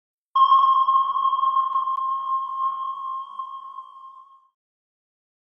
Download Sonar sound effect for free.
Sonar